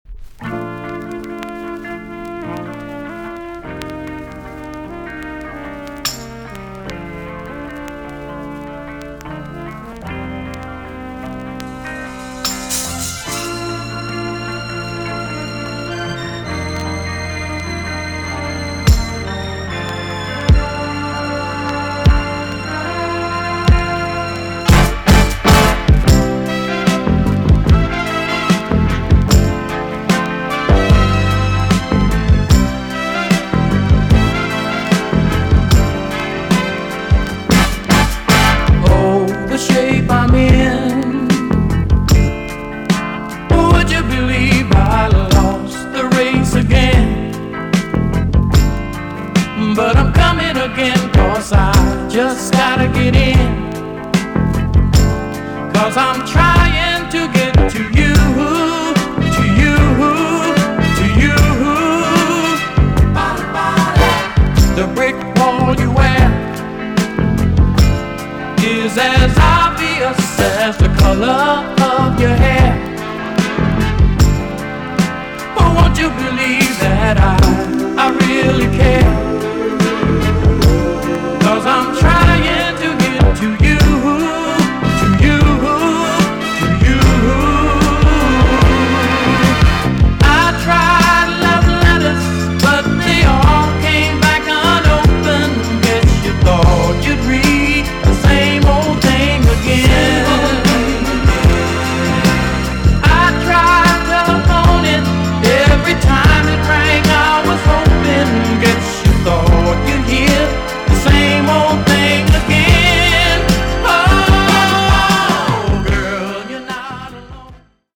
EX-~VG+ 少し軽いチリノイズがありますが良好です。
WICKED JAMAICAN SOUL TUNE!!